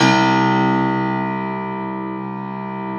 53e-pno02-C0.wav